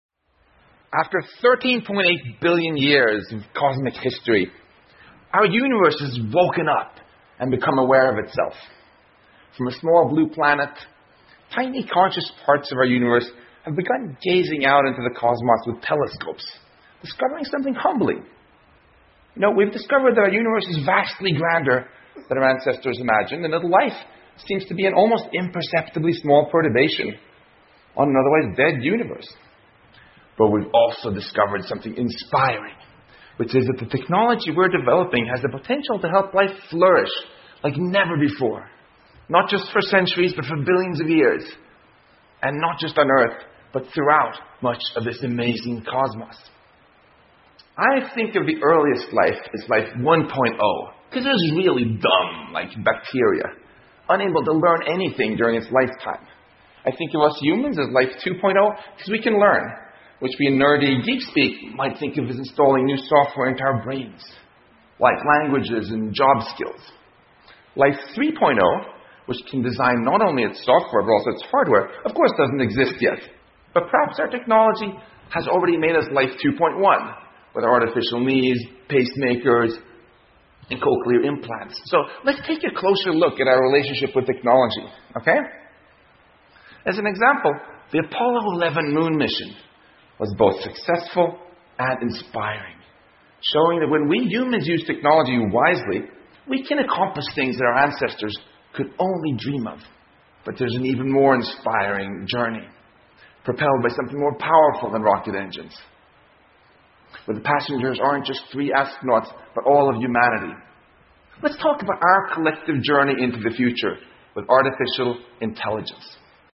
TED演讲:如何赋予AI力量而不是被它压倒() 听力文件下载—在线英语听力室